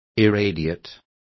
Complete with pronunciation of the translation of irradiated.